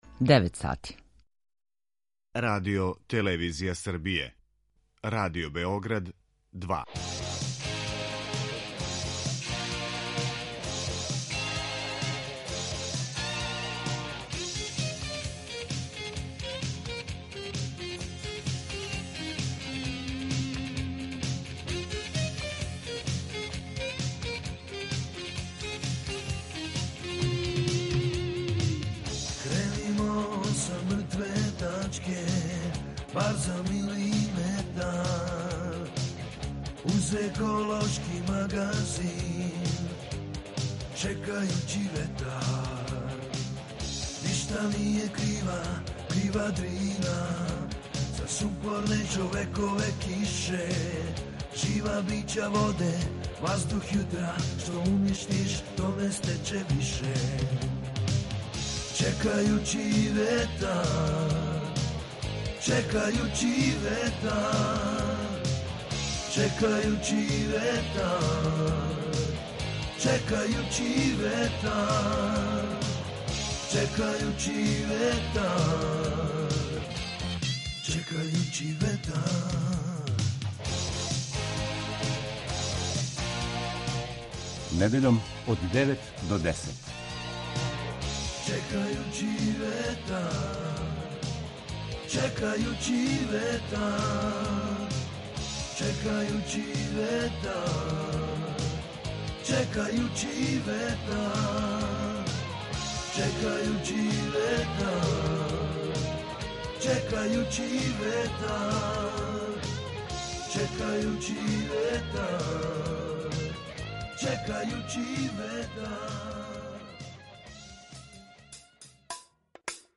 Čućete drugi deo snimka sa svečanosti u Sremskim Karlovcima i dobitnike Zelenog lista za prošlu godinu, po izboru žirija Radio Beograda 2 i Pokreta gorana Vojvodine, u akciji Tražimo zagađivača i zaštitnike životne sredine